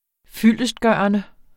Udtale [ ˌfylˀəsdˈgɶˀʌnə ]